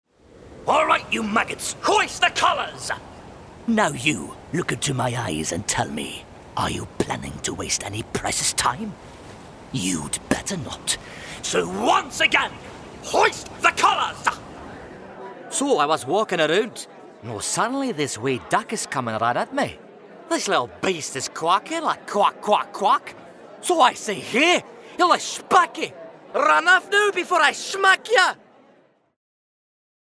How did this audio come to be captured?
The workspace in Amersfoort (NL) may be small, but with a professional microphone, audio interface and 1,2m x 1,2m booth, one cannot go wrong.